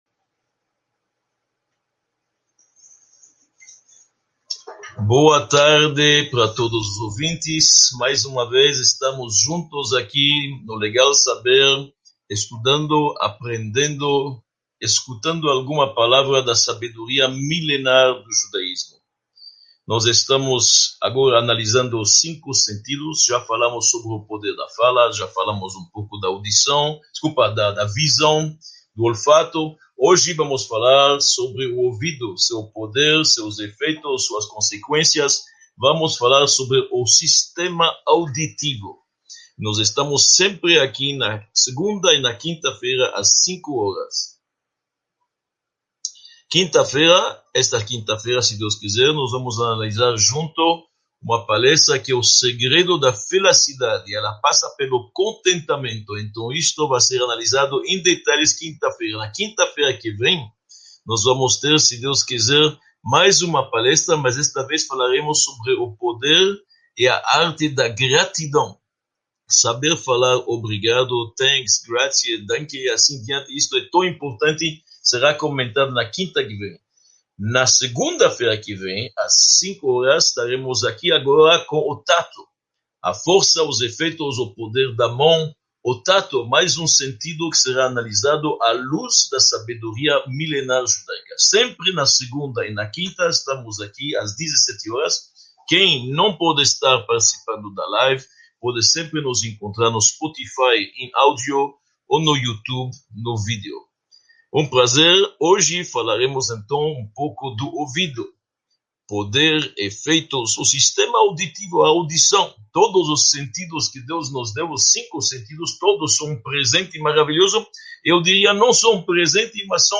31 – Ouvidos: sua força e efeitos | Módulo I – Aula 31 | Manual Judaico